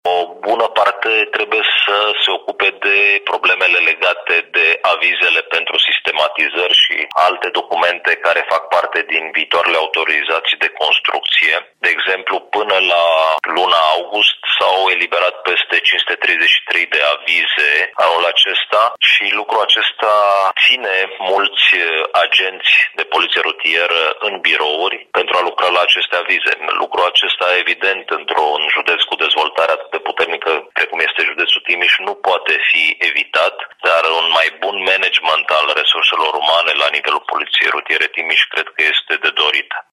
Problema a fost luată în discuție în cadrul comisiei de siguranță rutieră a județului, spune subprefectul Ovidiu Drăgănescu.